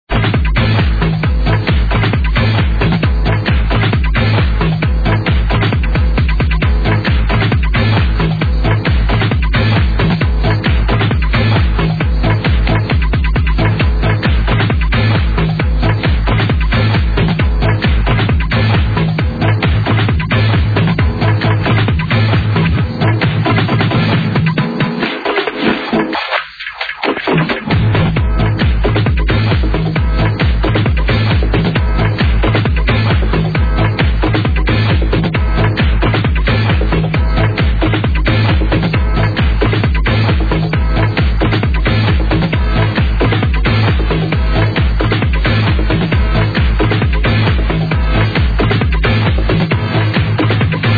funky tune
Its a diff sample from before without the vocals.